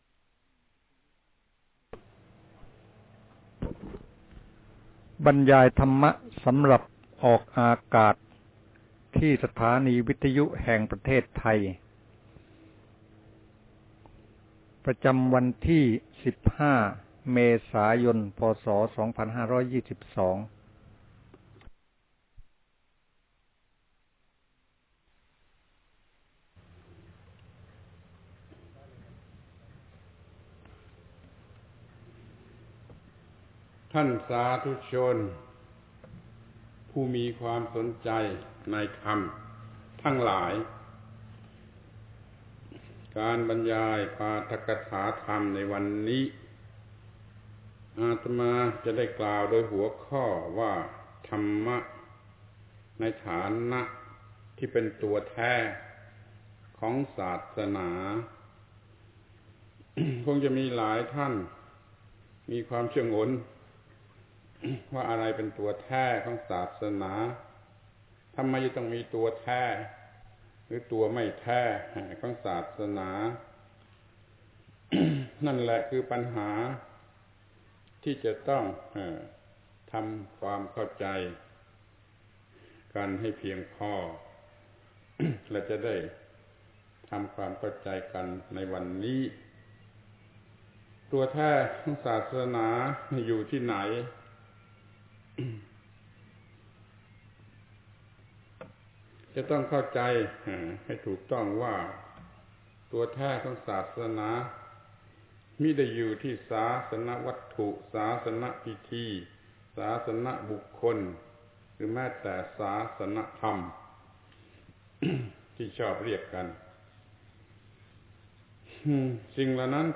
ปาฐกถาธรรมออกอากาศวิทยุประเทศไทย ศีลธรรมกลับมา ครั้งที่ 10 ธรรมะในฐานะสิ่งที่เป็นตัวแท้ของศาสนา